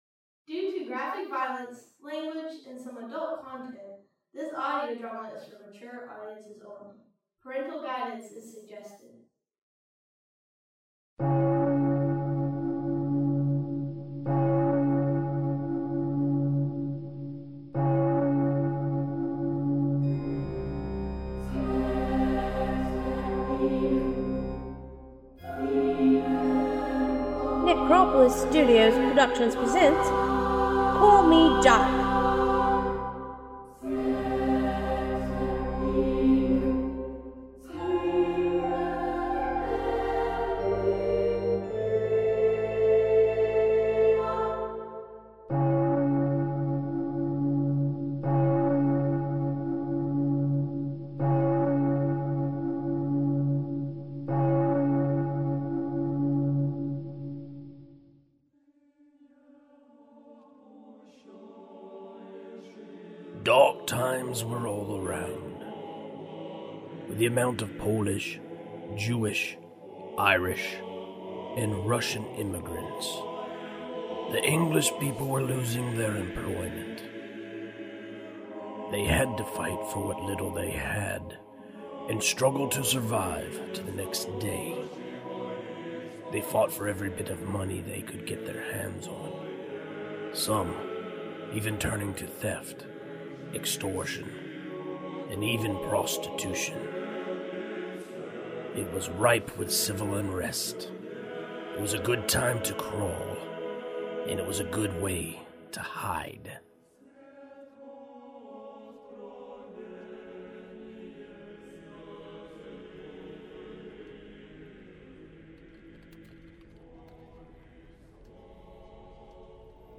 An original audio drama podcast.